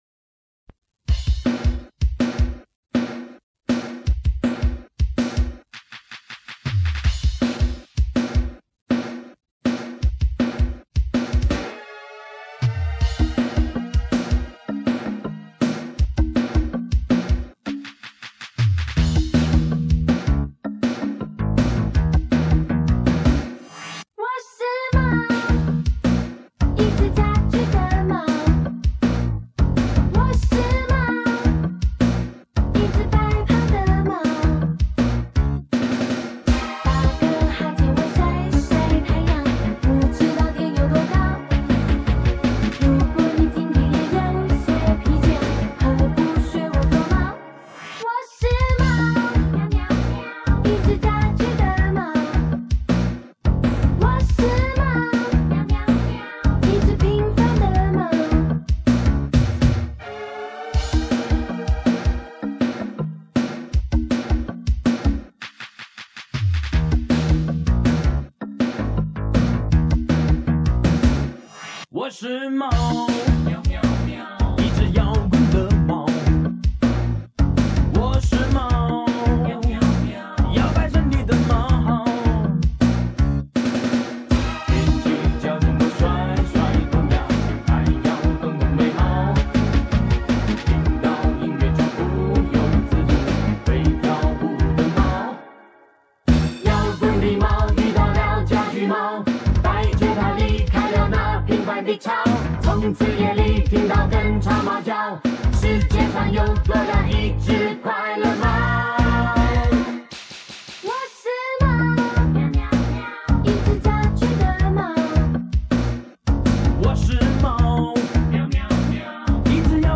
男声